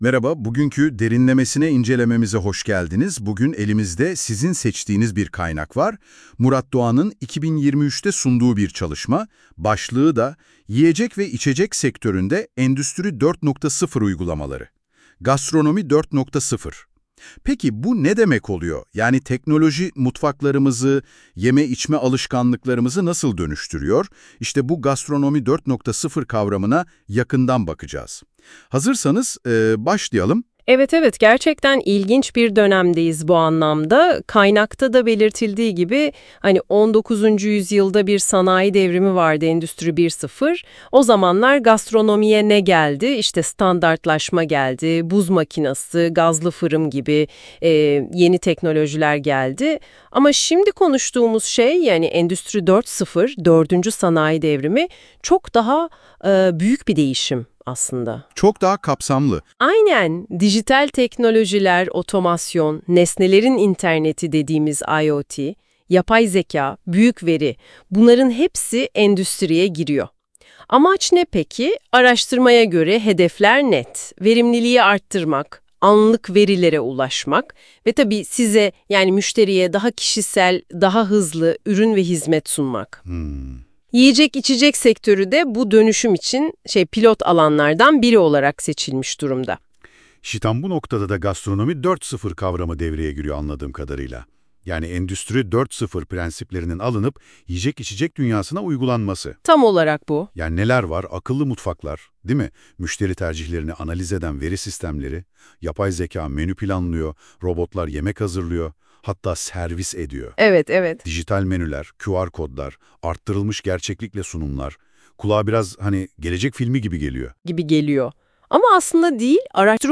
Gastronomi, Söyleşi